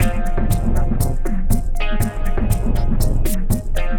Watching (Full) 120BPM.wav